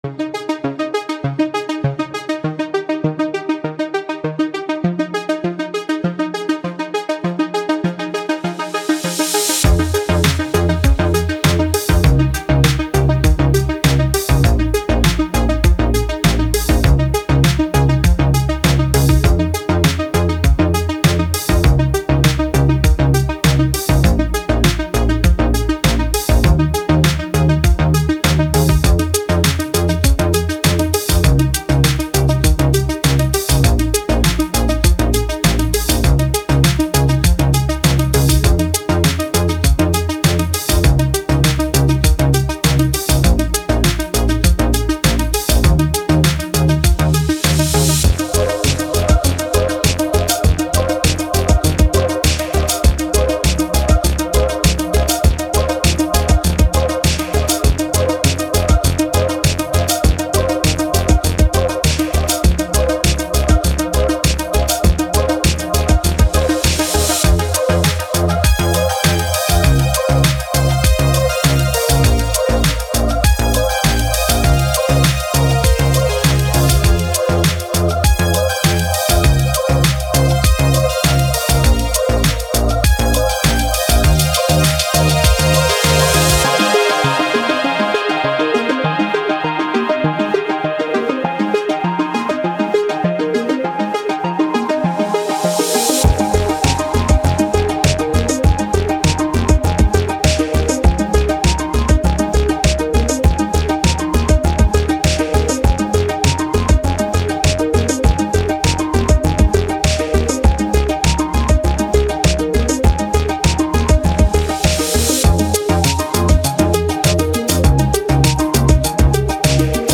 Synthwave, 80s, Electronic, Electro